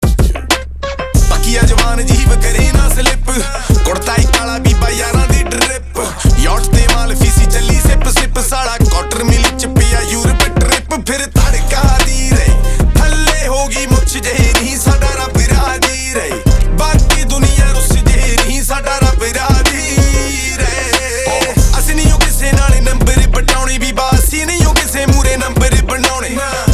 6TypeRelaxing / Soothing Instrumental Tone